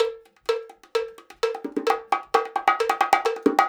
130BONGO 10.wav